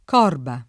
corba [ k 0 rba ]